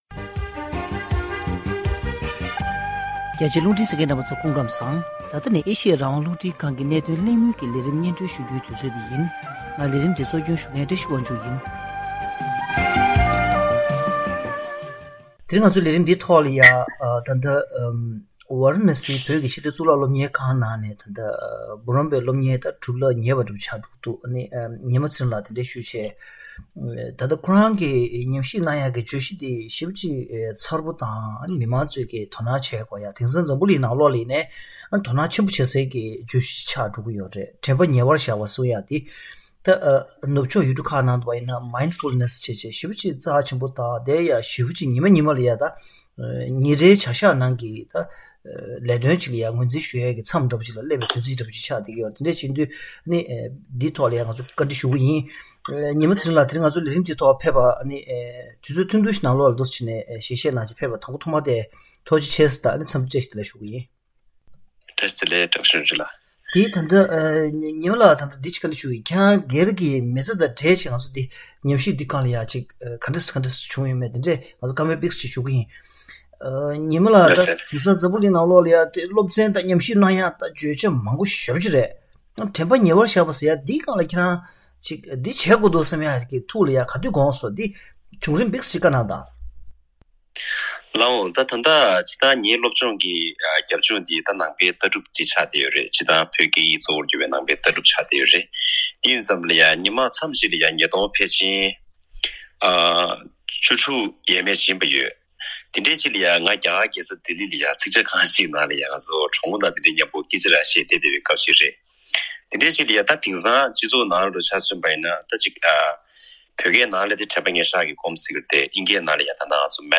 གཤམ་ལ་གནད་དོན་གླེང་མོལ་གྱི་ལས་རིམ་ནང་།